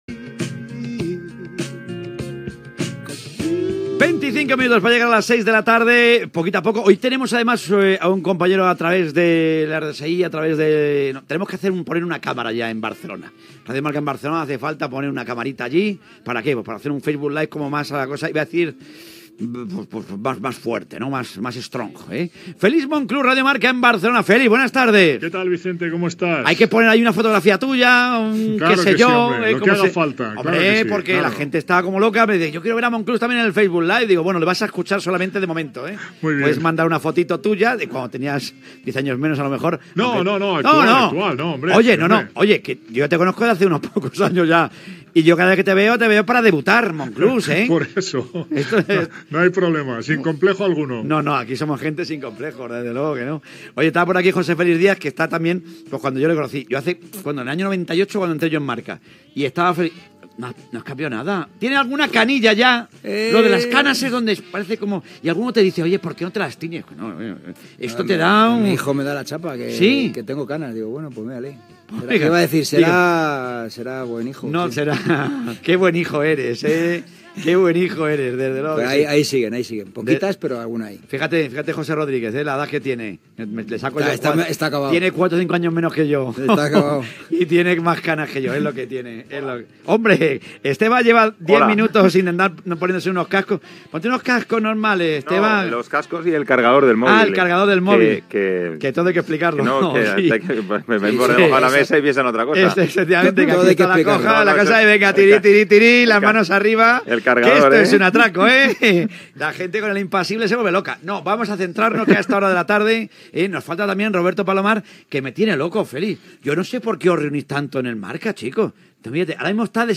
Presentació de l'equip, diàleg sobre cinema, valoracions sobre el destí del jugador de futbol Neymar Gènere radiofònic Esportiu